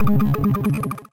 探索8比特 " 目标完成
描述：我认为这种语气用于通知新目标，或者如果您选择一个字符。
Tag: 复古 8位 样品